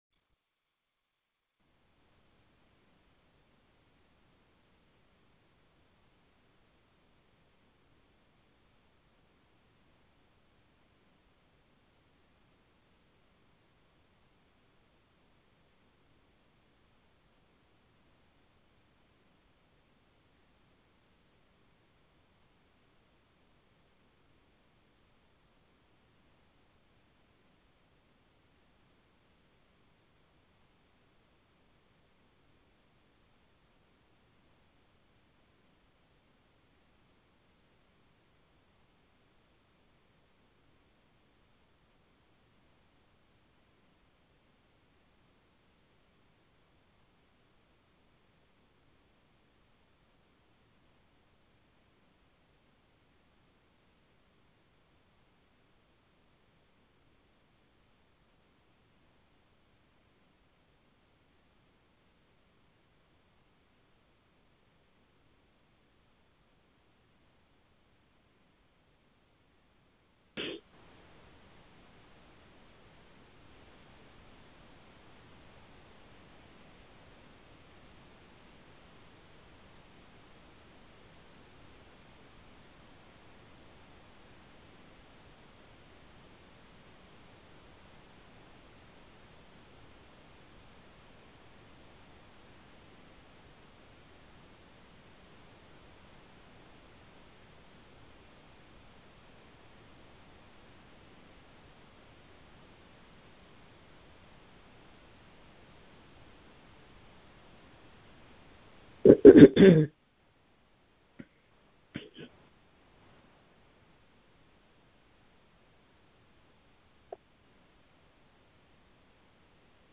Prayers for Men taken from the weekly prayer conference line.